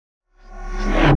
TM88 JetFX.wav